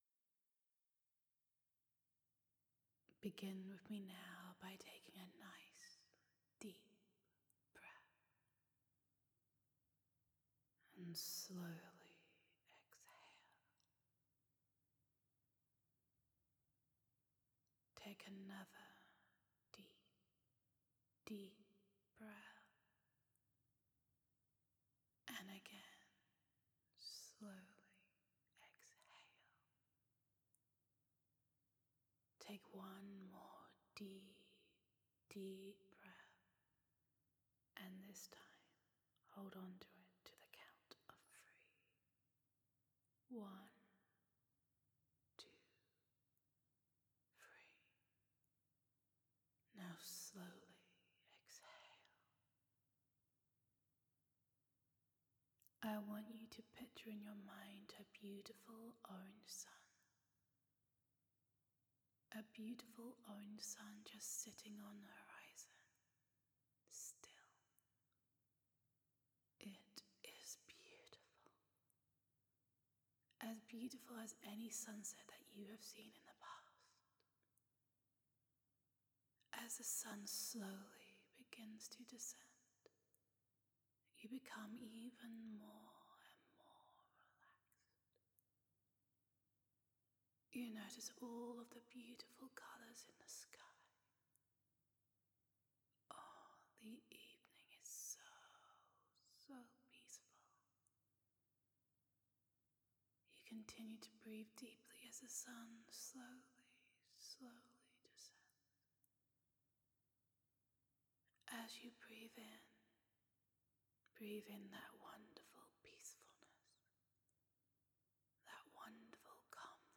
Guided Relaxation